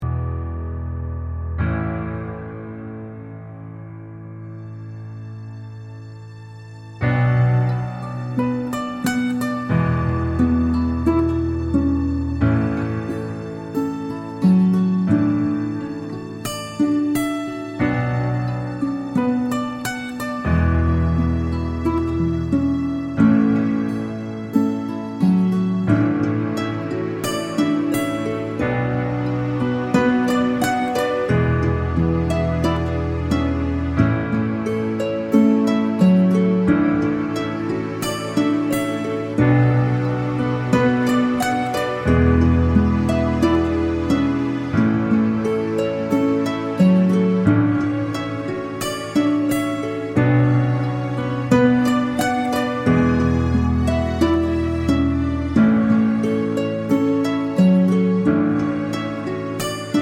no Backing Vocals R'n'B / Hip Hop 3:26 Buy £1.50